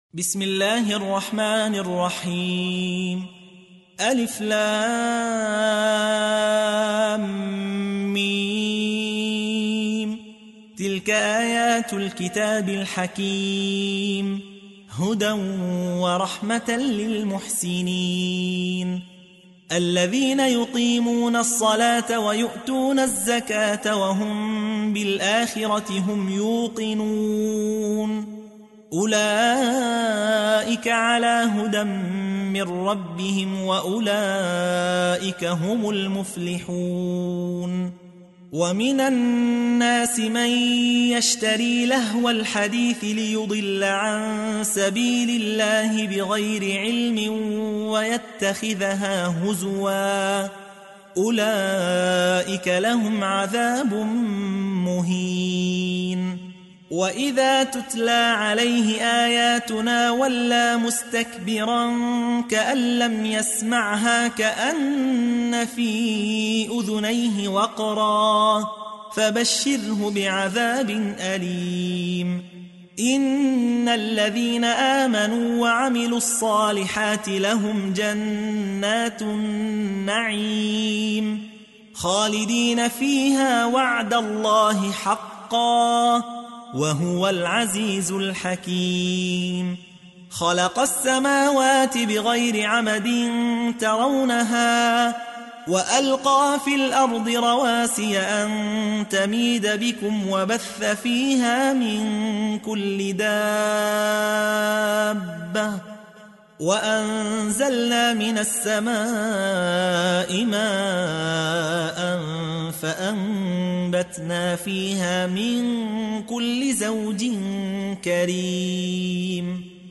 موقع يا حسين : القرآن الكريم 31.